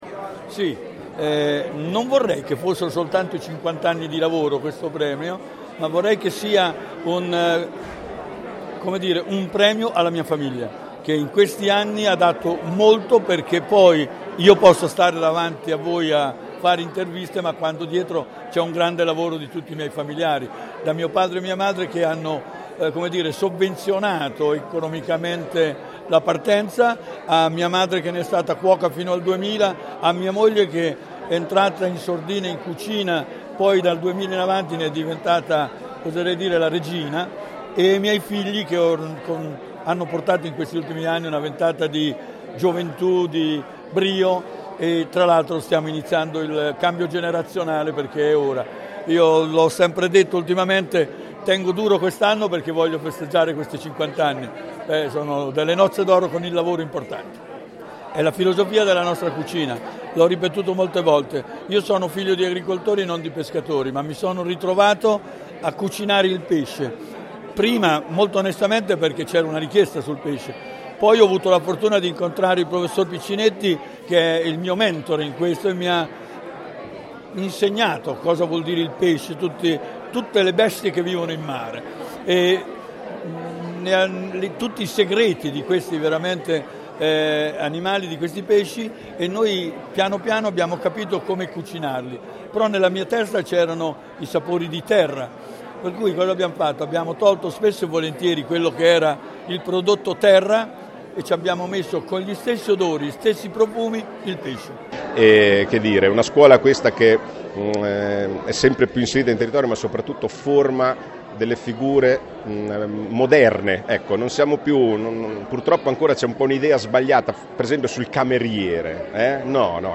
Le nostre interviste ai protagonisti della serata.